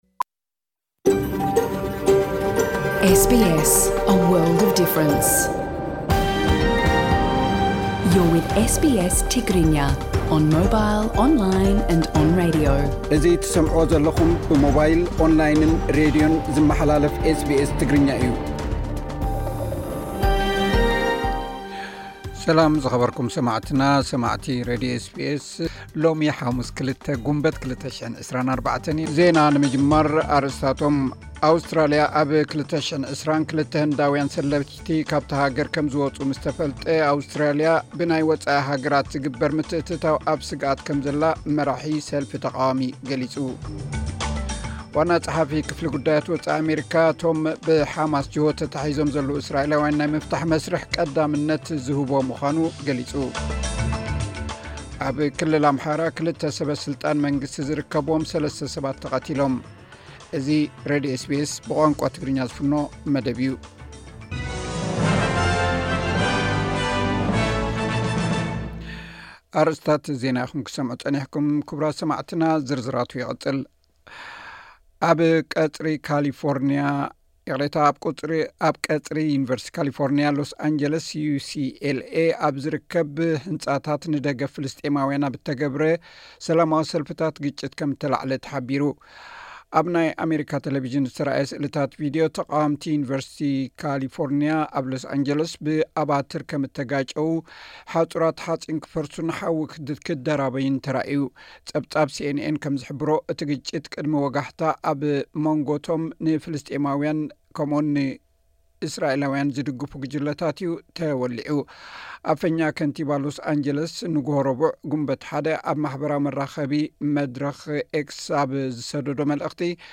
ዜናታት ኤስ ቢ ኤስ ትግርኛ (02 ግንቦት 2024)